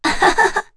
Kara-Vox_Happy1.wav